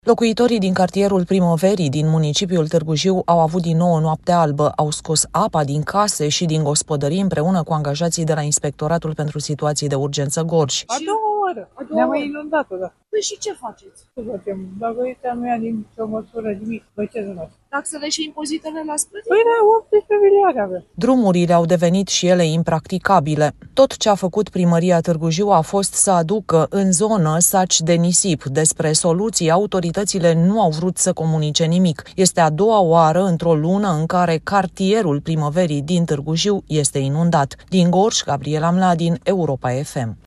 „Este a doua oară, ne-a mai inundat o dată”, spune o femeie.